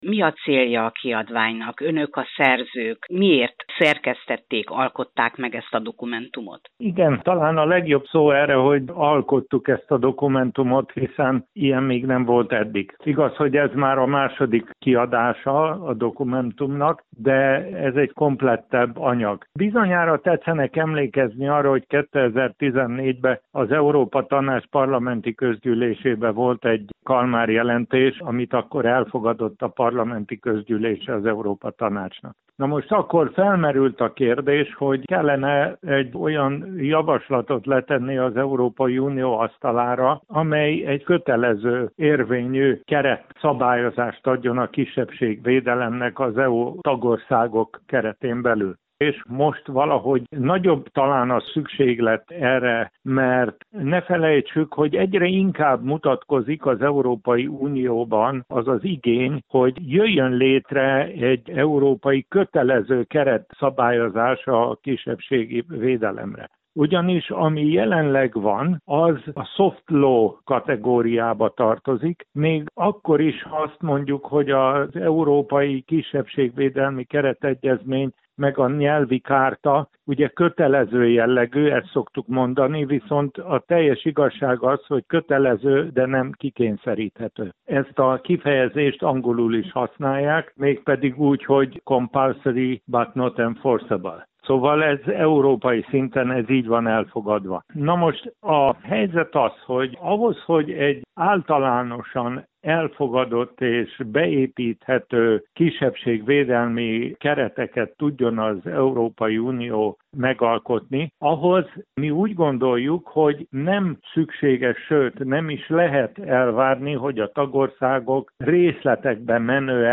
készített interjút